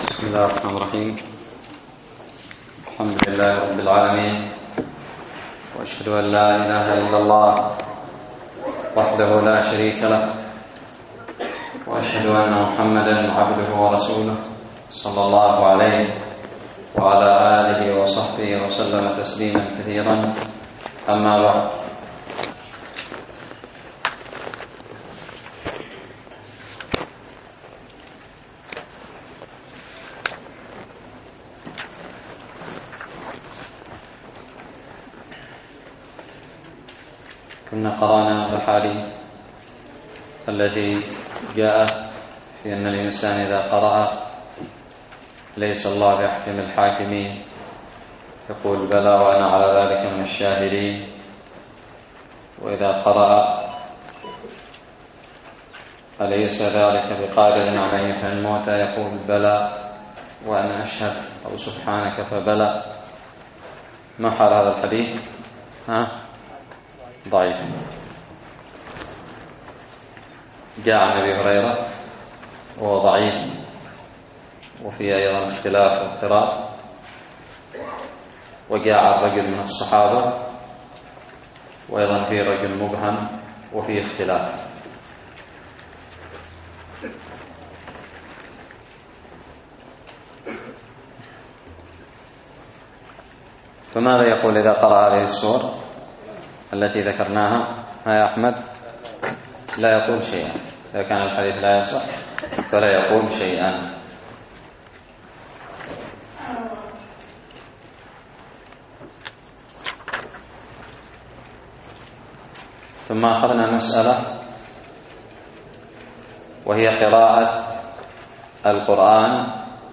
الدرس الثالث والعشرون من شرح كتاب التبيان في آداب حملة القرآن
ألقيت بدار الحديث السلفية للعلوم الشرعية بالضالع